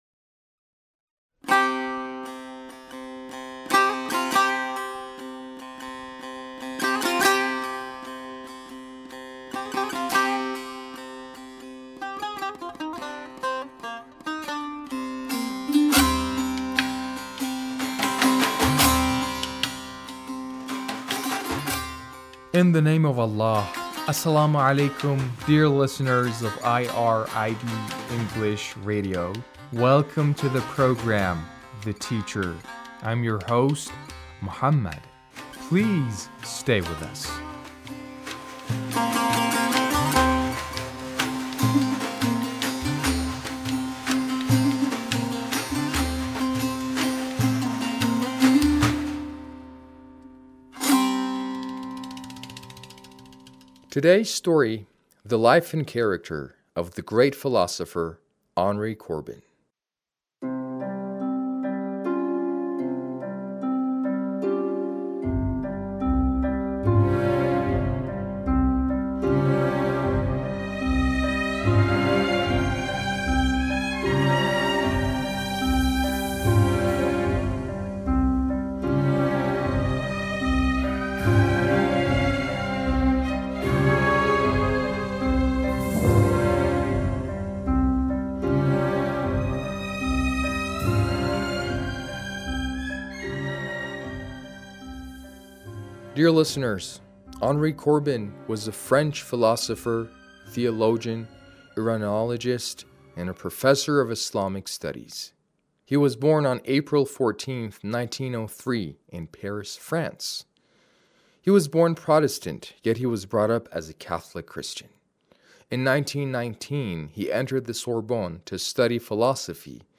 A radio documentary on the life of Dr. Henry Corbin - 1